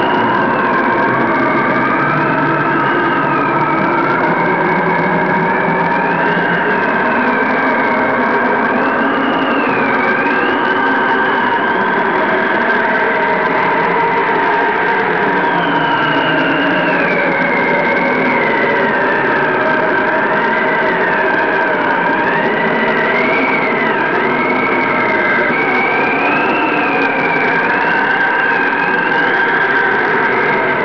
Coldwind
ColdWind.wav